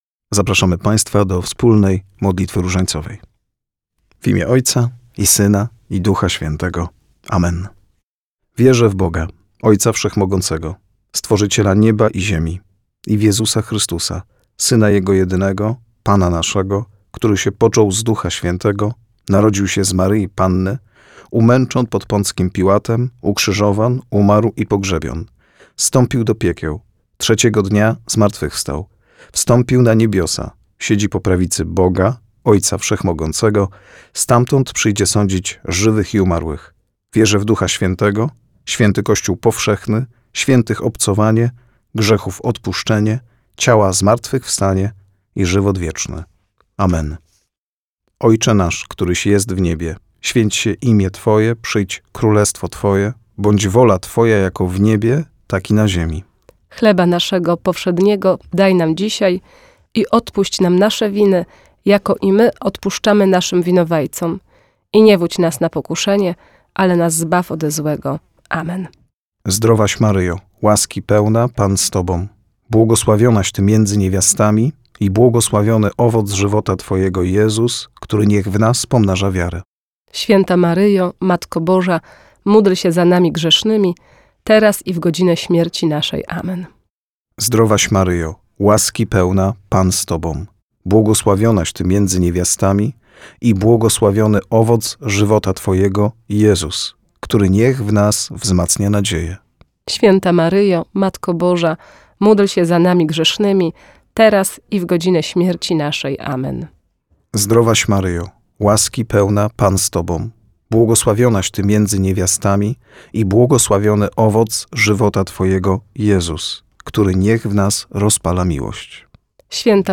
U progu wakacji weszliśmy do studia Radia eM, żeby nagrać wybrane przez Was w sondzie modlitwy.